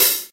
hihat.mp3